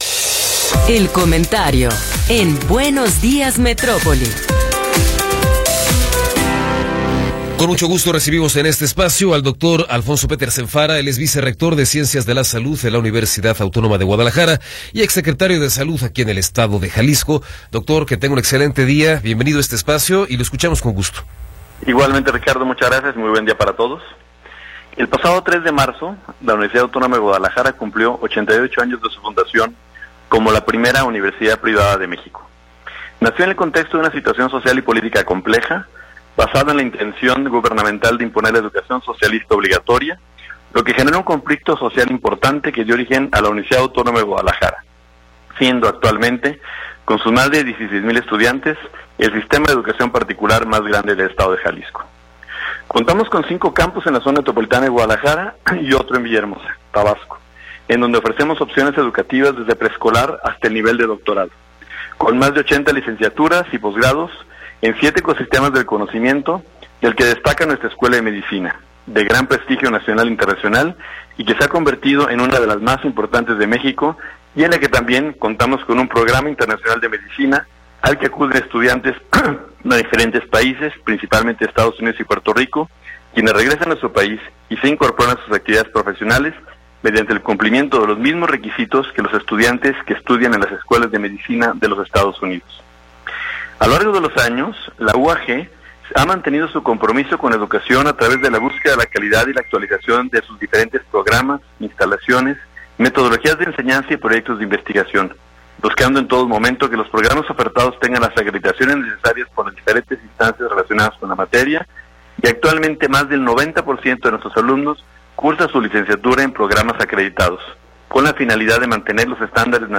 Comentario de Alfonso Petersen Farah
El Dr. Alfonso Petersen Farah, vicerrector de ciencias de la salud de la UAG y exsecretario de salud del estado de Jalisco, nos habla sobre la Universidad Autónoma de Guadalajara a 88 años de su fundación.